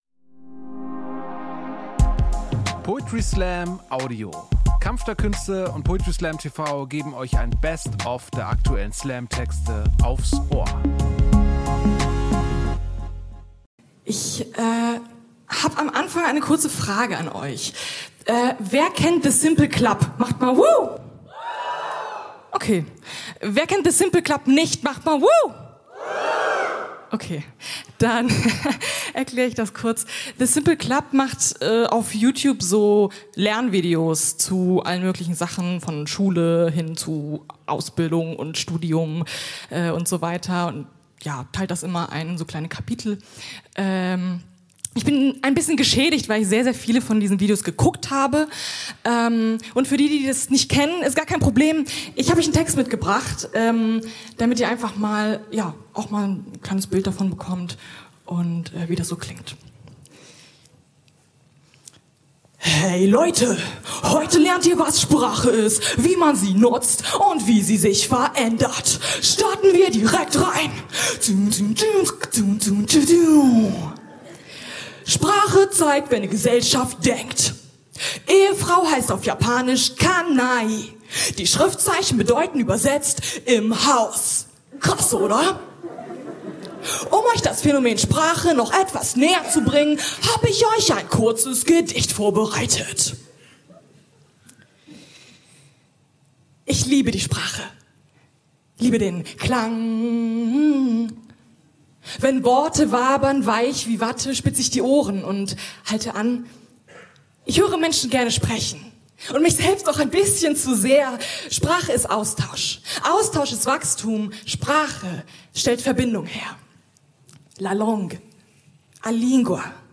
Schauspielhaus, Hamburg Kampf der Künste TV Website: